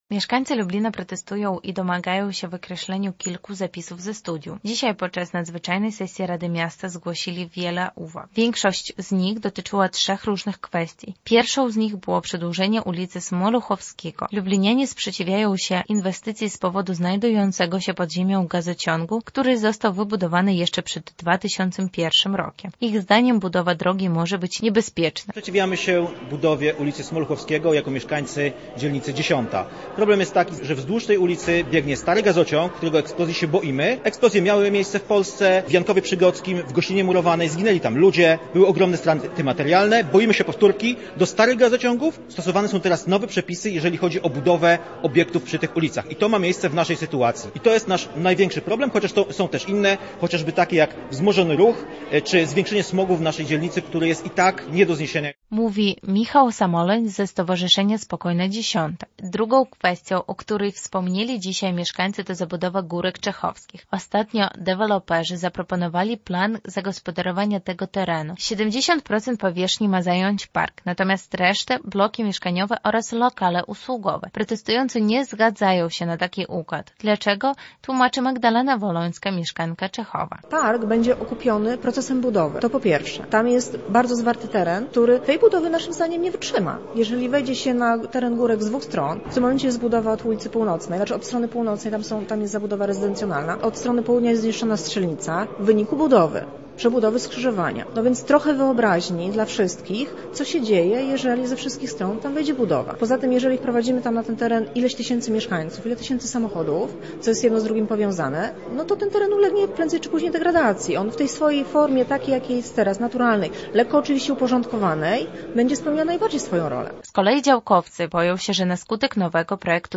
W Ratuszu pojawili się nie tylko radni, ale i mieszkańcy.
nadzwyczajna sesja rady miasta